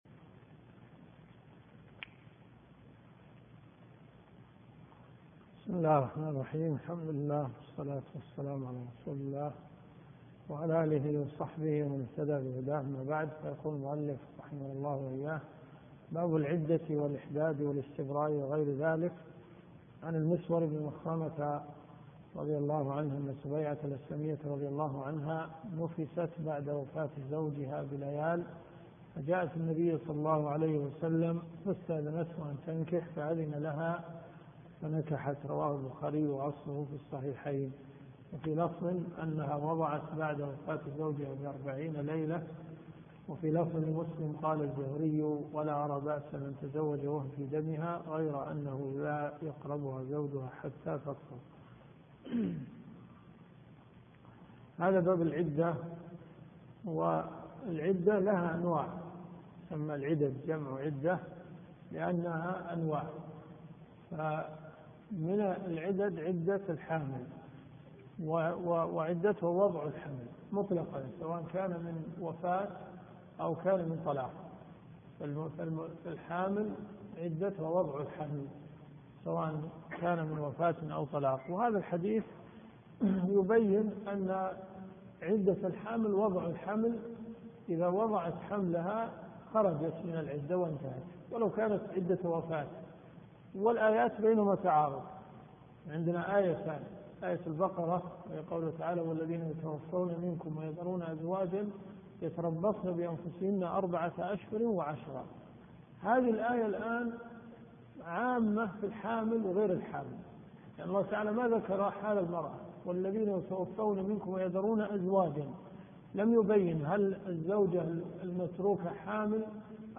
الرياض . حي النخيل . جامع الاميرة نورة بنت عبدالله